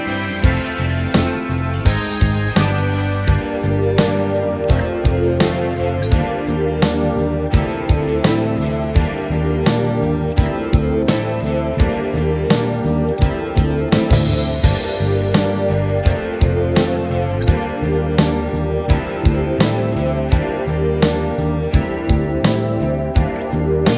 Twofers Medley Pop (1980s) 3:55 Buy £1.50